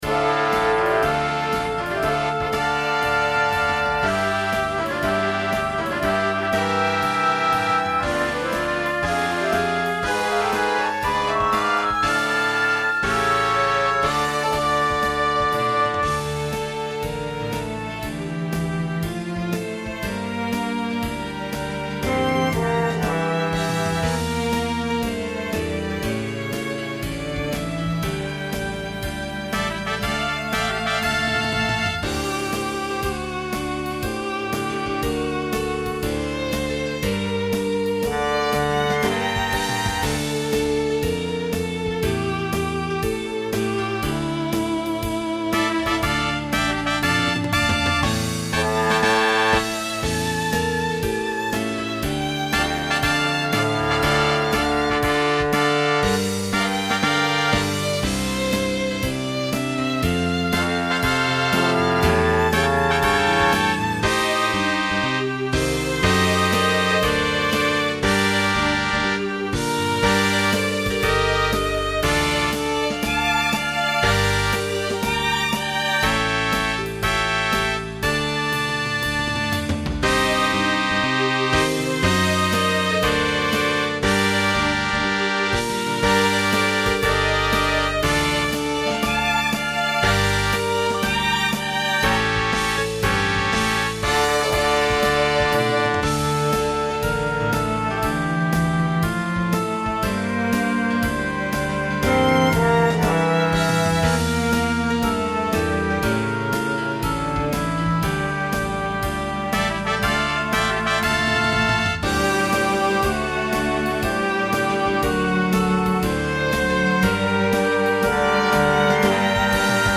Слушать минус